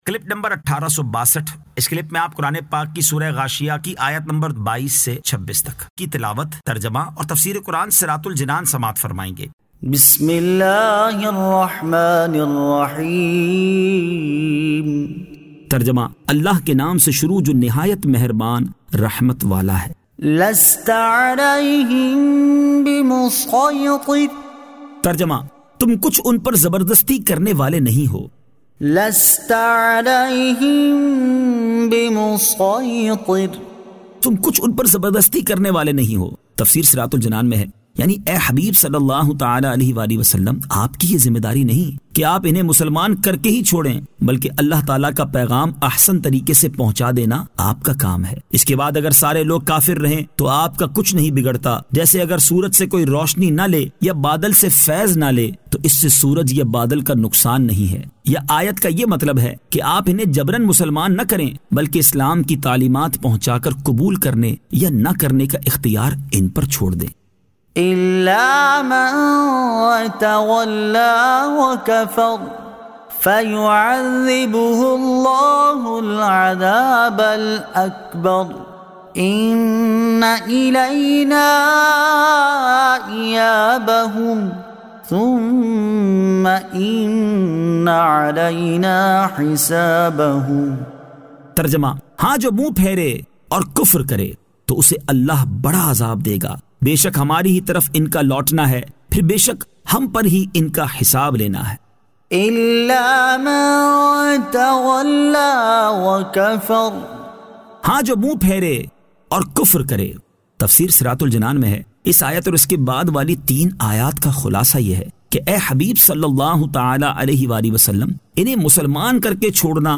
Surah Al-Ghashiyah 22 To 26 Tilawat , Tarjama , Tafseer